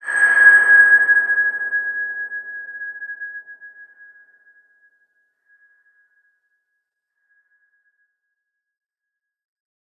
X_BasicBells-G#4-mf.wav